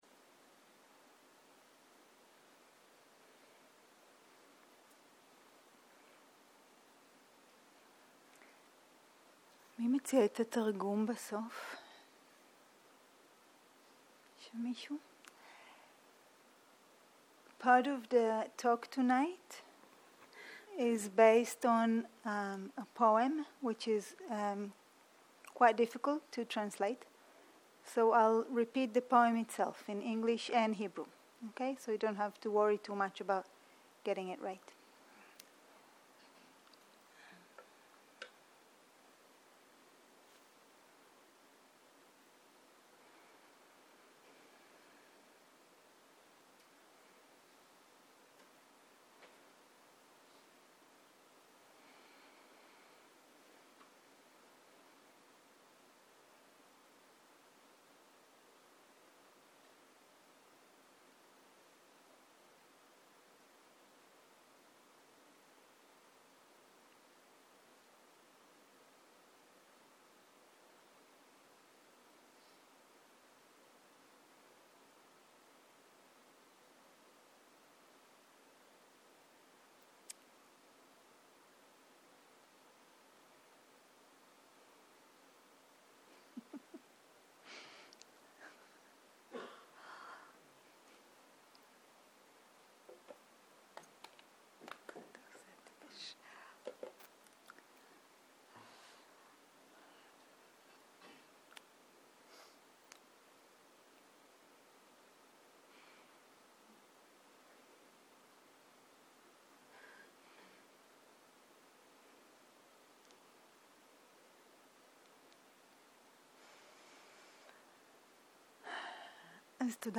שיחת דהרמה